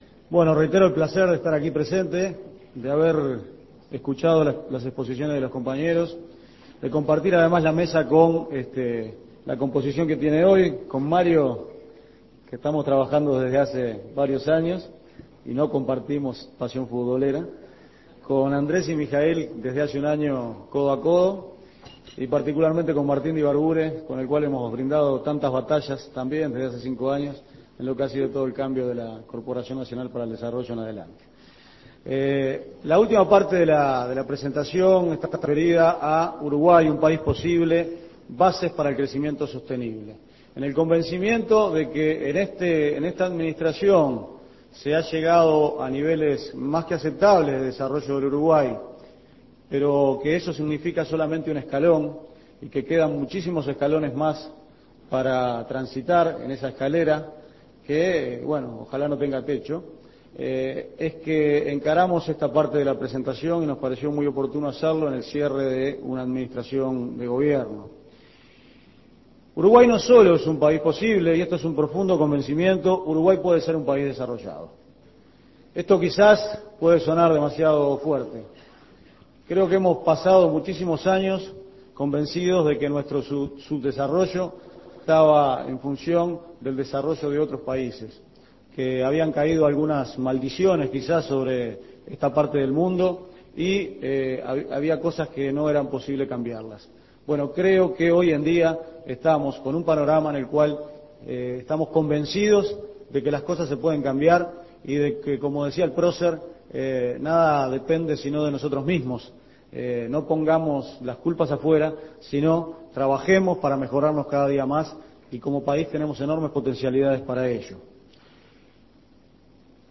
Escuche la segunda exposición de Álvaro García, ministro de Economía